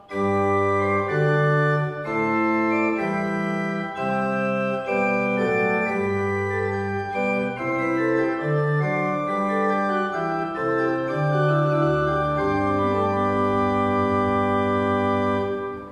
kostel sv. Jana Nepomuckého
Nahrávky varhan:
Vsemina, Kopula major a minor, Principal 2.mp3